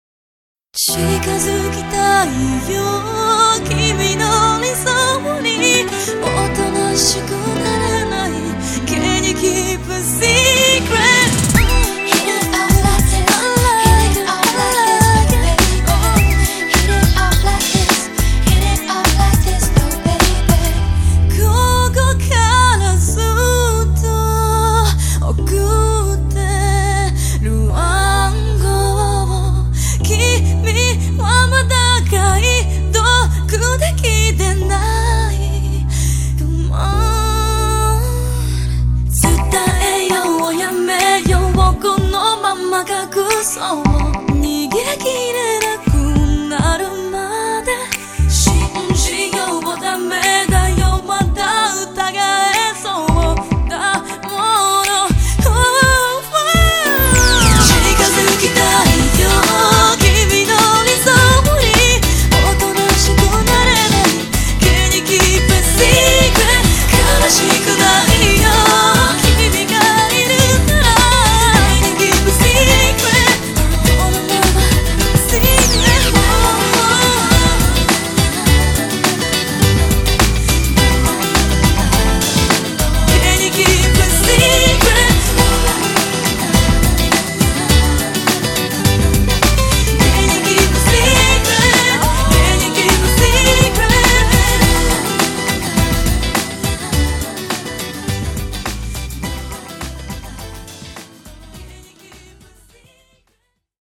BPM104
Audio QualityPerfect (High Quality)
soulful pop track